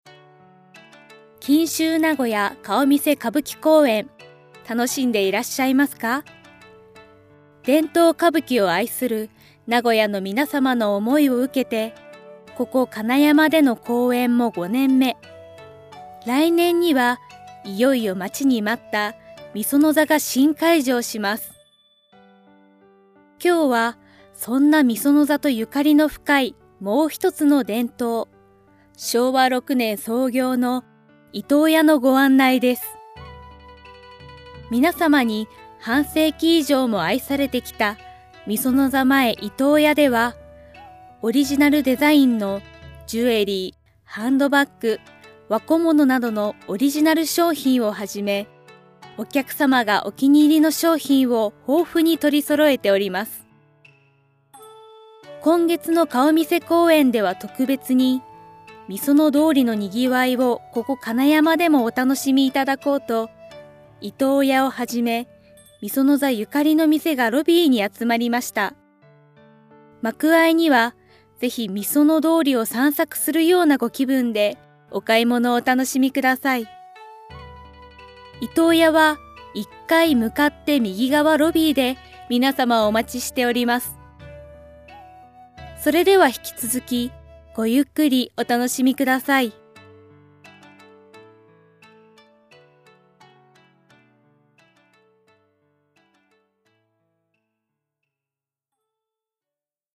イヤホンガイド協賛放送は歌舞伎俳優のナレーションによる耳から聴く広告媒体です。
イヤホンガイドパンフ2018年4月　名古屋御園座こけら落とし公演、松本幸四郎丈改め二代目　松本白鸚襲名披露公演で老舗「居東屋」イヤホンガイドCM制作